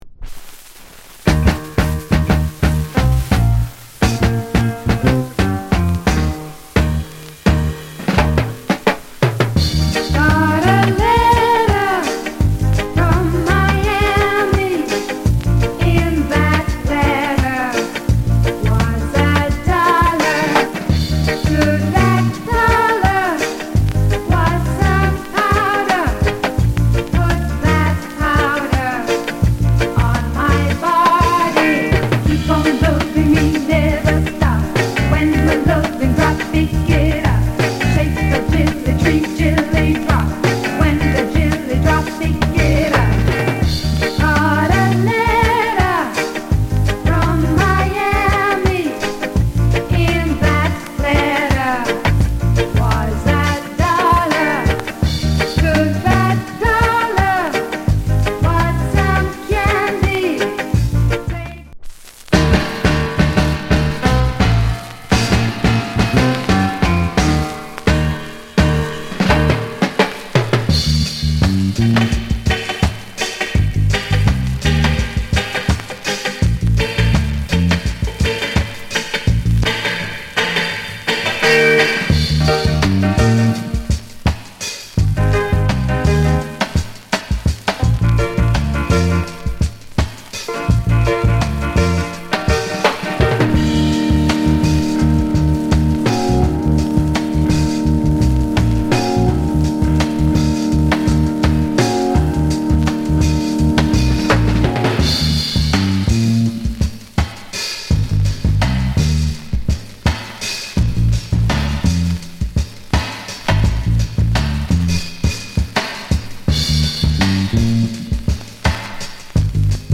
アップ・テンポ、アイランドディスコ。 ** プレスノイズあり。